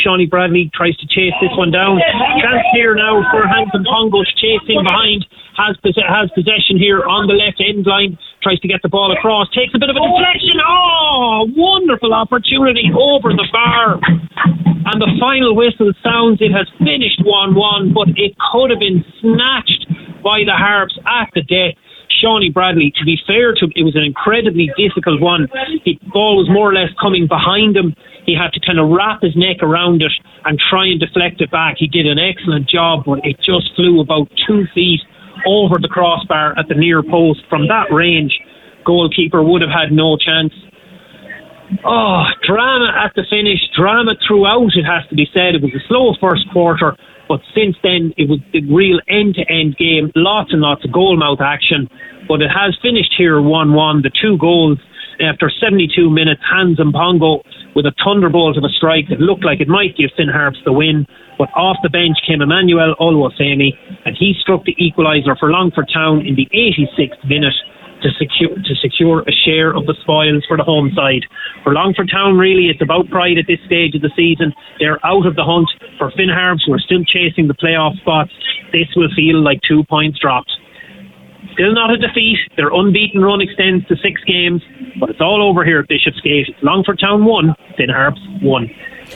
live on Highland at full time